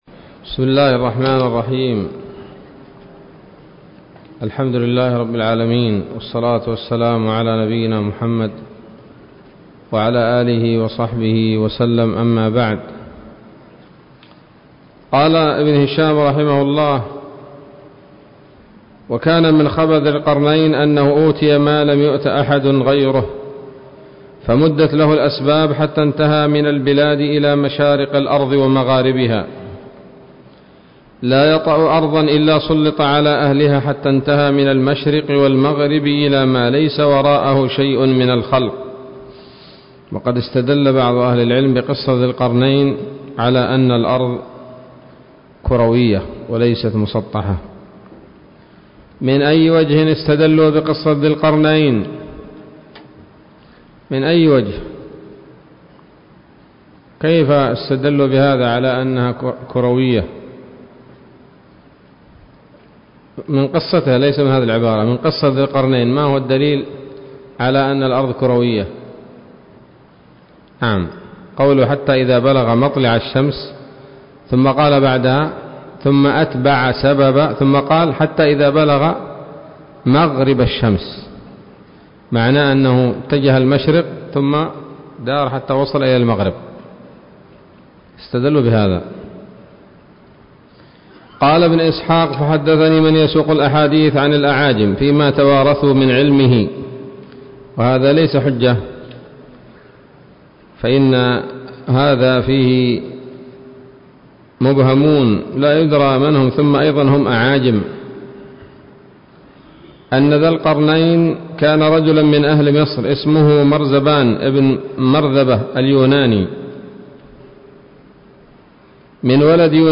الدرس الثاني والثلاثون من التعليق على كتاب السيرة النبوية لابن هشام